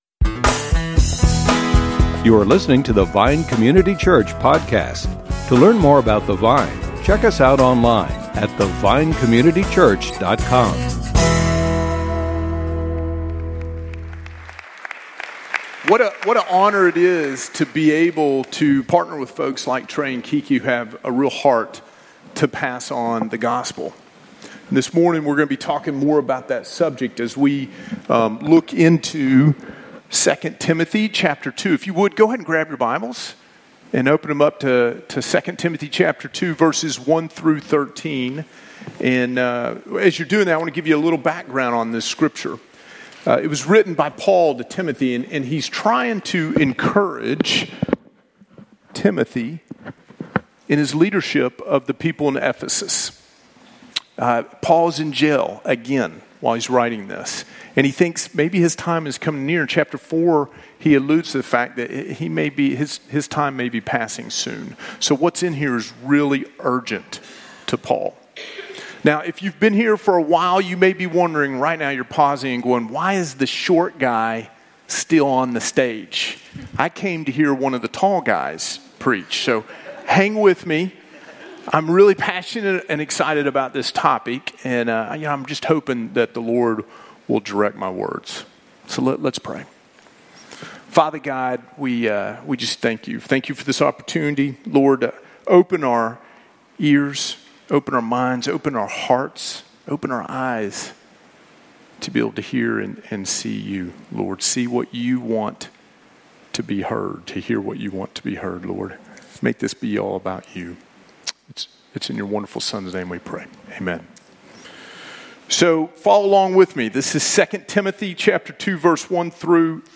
Sermons |